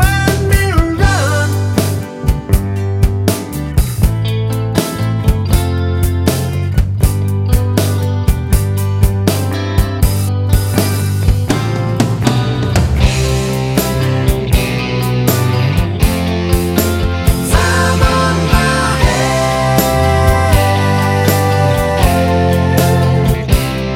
Minus Harmonica Pop (1970s) 4:42 Buy £1.50